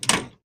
break.ogg